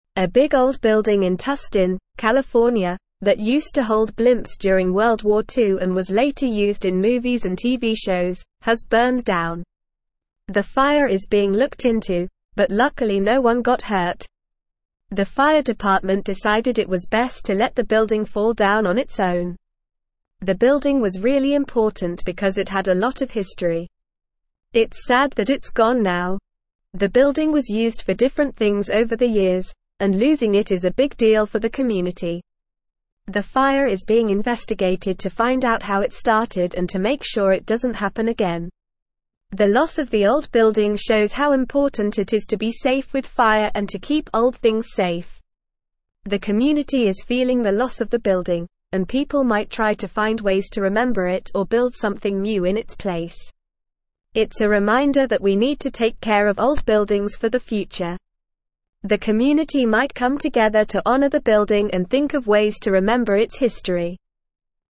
Normal
English-Newsroom-Upper-Intermediate-NORMAL-Reading-Historic-World-War-Two-Era-Blimp-Hangar-Engulfed-in-Flames.mp3